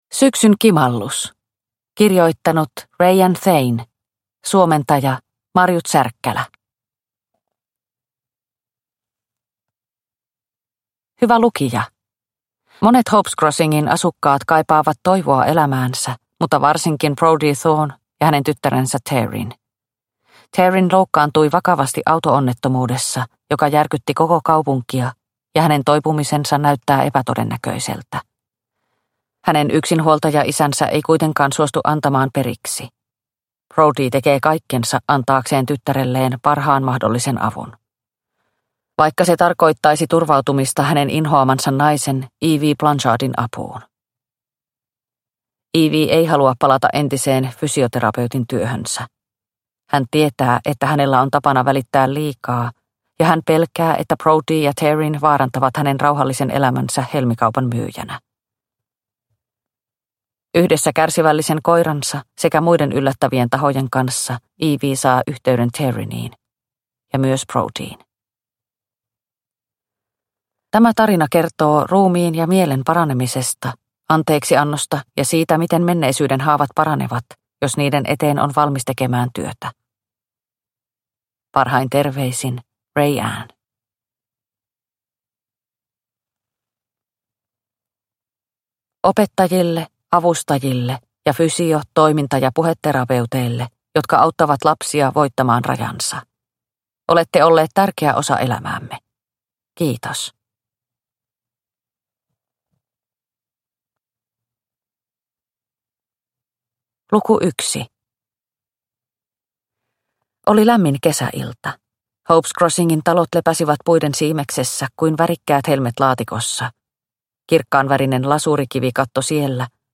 Syksyn kimallus – Ljudbok – Laddas ner